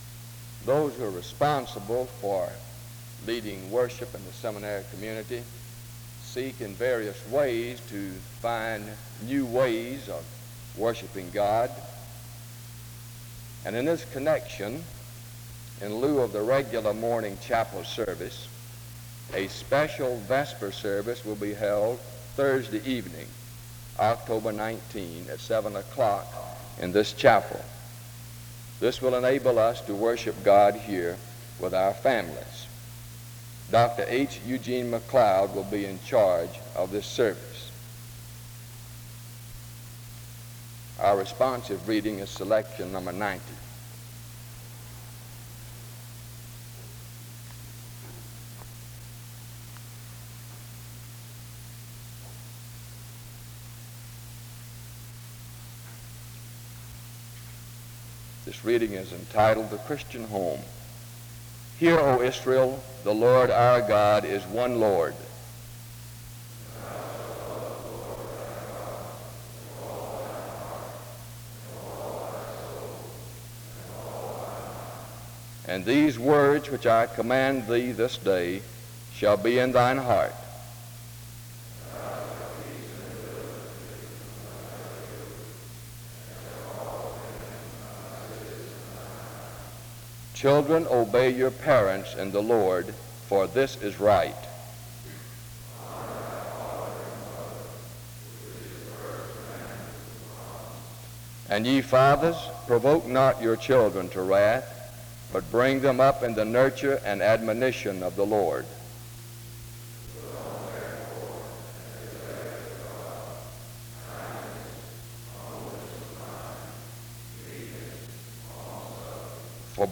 The service starts with an announcement from 0:00-0:39. A responsive reading takes place from 0:40-2:29. A prayer is offered from 2:30-3:56.